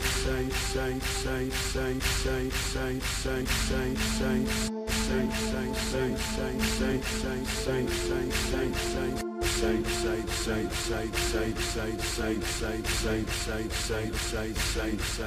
ذکر حسین استودیویی شور | vst ذکر حسین طولانی برای مداحی
ذکر حسین استودیویی شور اجرای ذکر حسین شور با کیفیت استودیویی در 18 نت در سرعت های مختلف
zekre-hossein-demo-shour.mp3